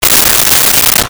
Shower Curtain Open 01
Shower Curtain Open 01.wav